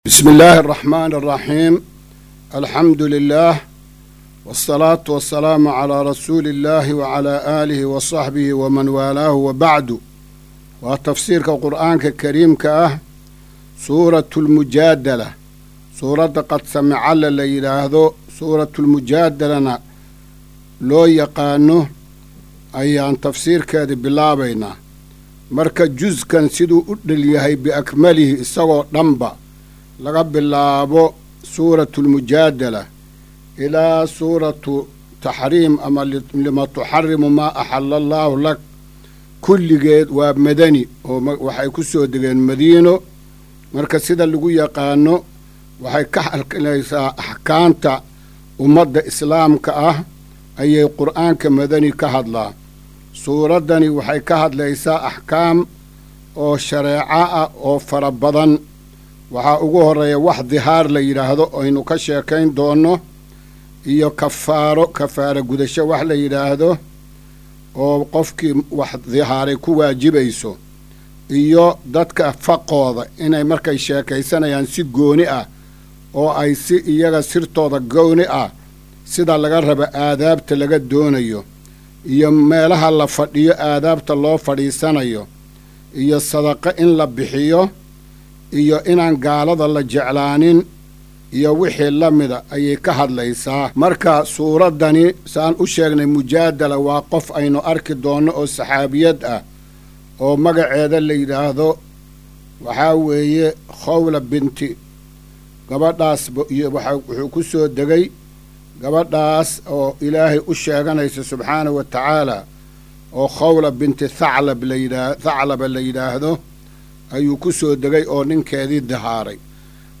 Maqal:- Casharka Tafsiirka Qur’aanka Idaacadda Himilo “Darsiga 258aad”